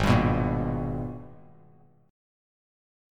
G#mM9 chord